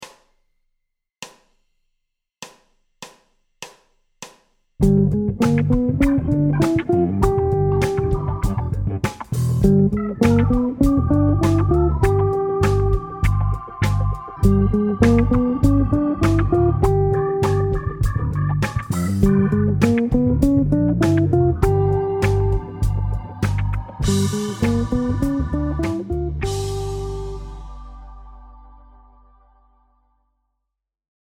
Phrases sur accords de dominante altérés # Accord Abrégé G13 b9 Phrase 01 : Montée pure et simple de la gamme de G demi-ton / ton G alt Phrase 02 : Sur une mesure, const…